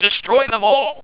The results are quite impressing – the screenshot below shows a range of Salamander’s “Destroy them all!” speech sample.
Initial comparison results show that the gate-level design produces identical audio waveforms for most of the frames.
salamander_gl.wav